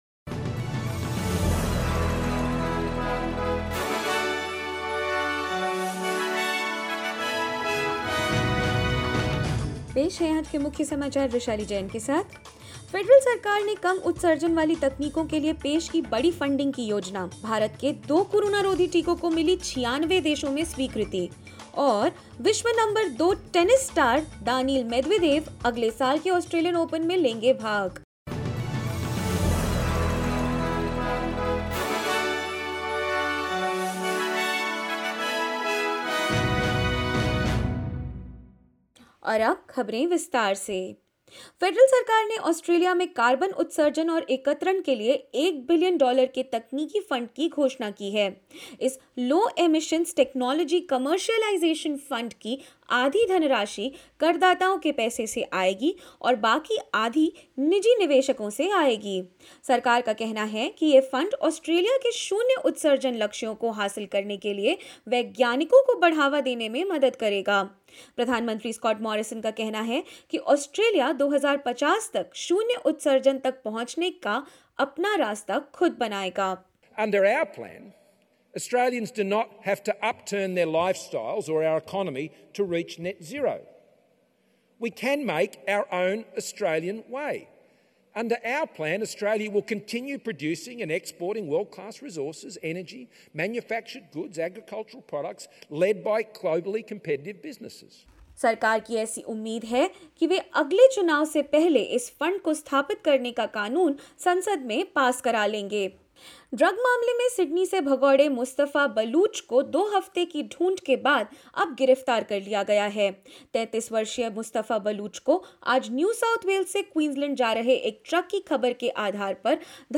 In this latest SBS Hindi news bulletin of Australia and India: Federal government plans to fund a billion dollar for the development of low-emissions technologies; The world number two tennis star Daniil Medvedev intends to compete at next year's Australian Open and more.